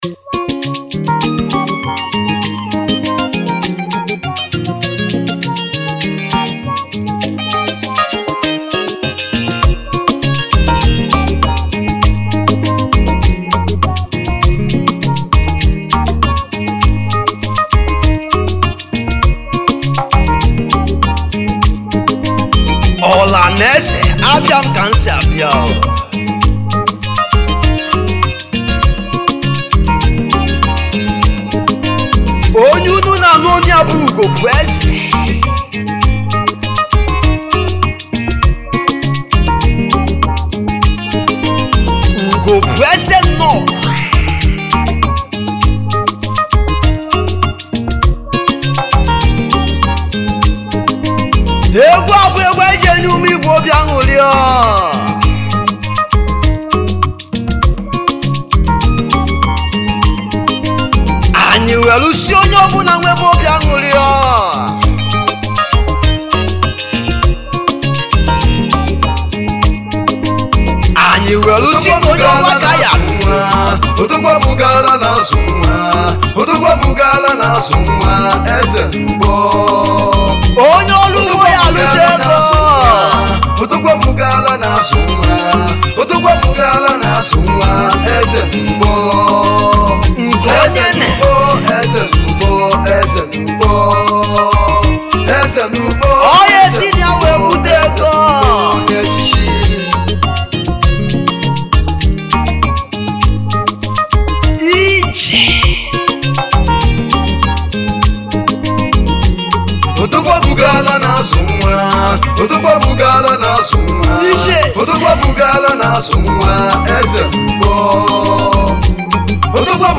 highlife
Highlife Traditional Free